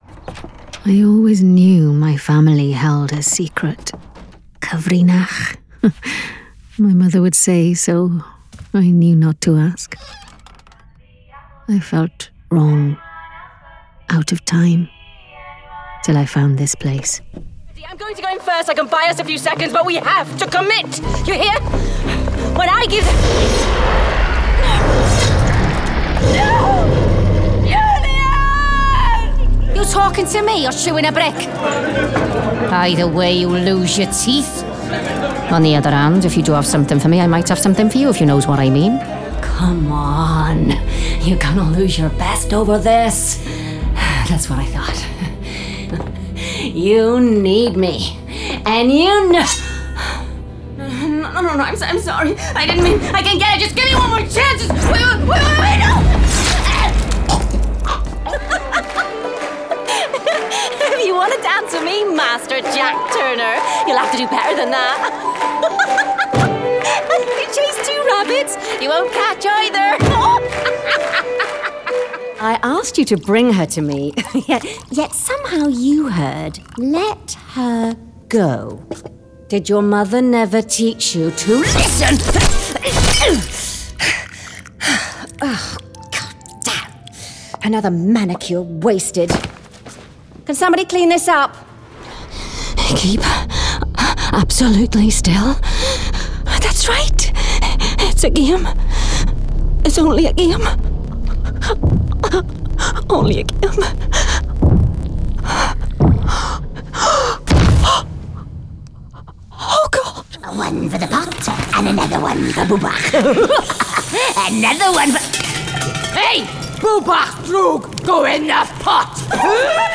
Warm, natural, approachable and versatile with a gentle authority.
Games Reel – Various Voices